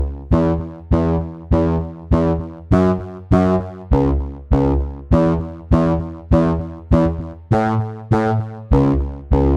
Dance music bass loops 2
Dance music bass loop - 100bpm 53